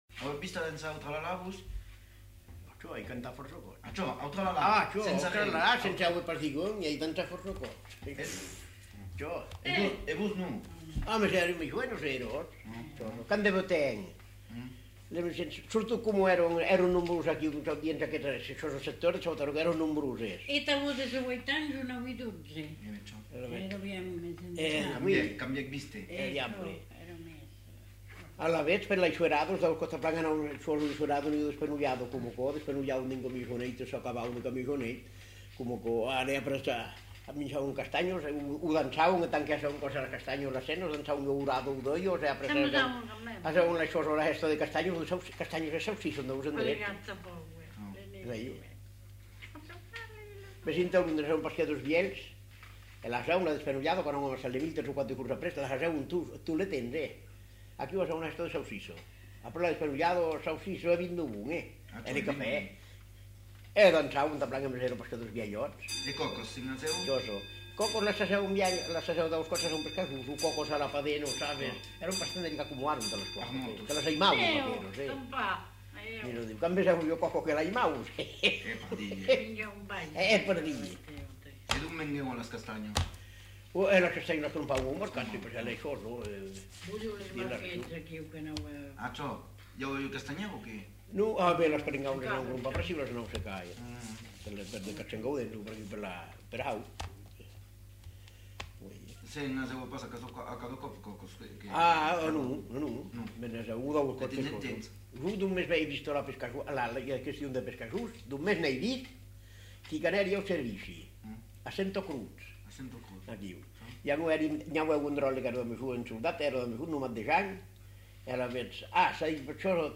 Lieu : Espaon
Genre : témoignage thématique